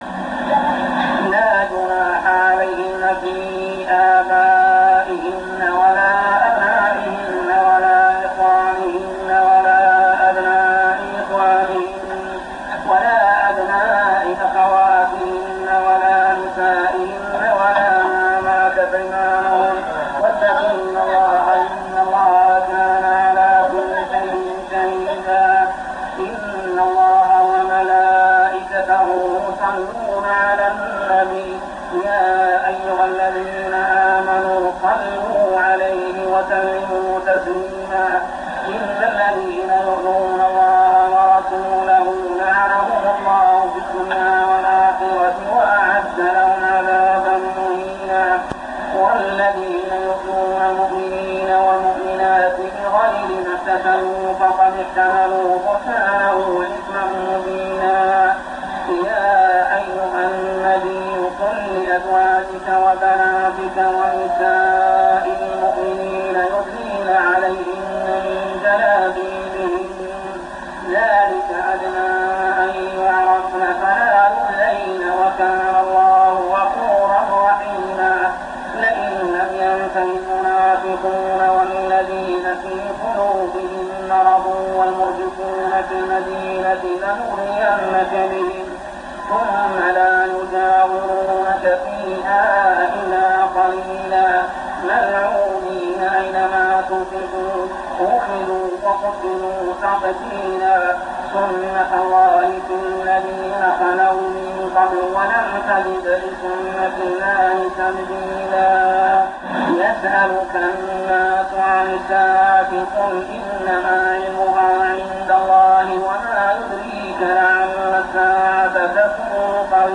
صلاة التراويح عام 1397هـ سورة الأحزاب 55-73 | Tarawih prayer Surah Al-Ahzab > تراويح الحرم المكي عام 1397 🕋 > التراويح - تلاوات الحرمين